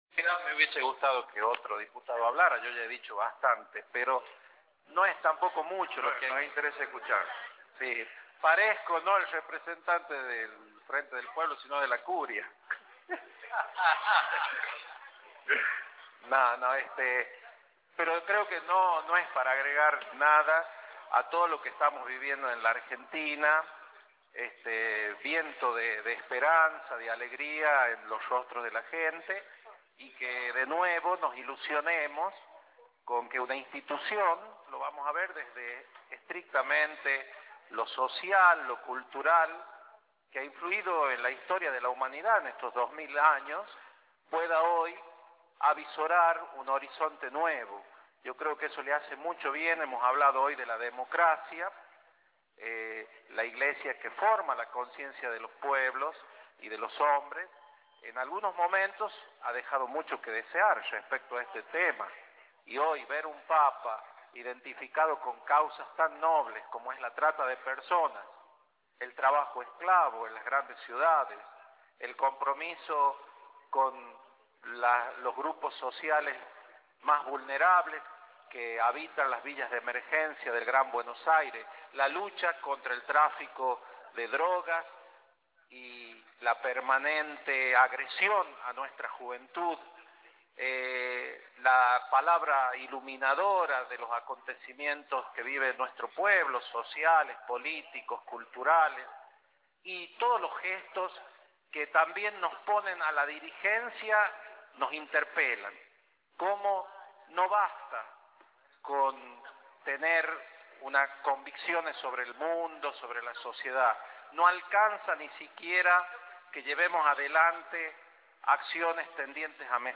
Délfor Brizuela, diputado provincial Alejandra Oviedo, diputada provincial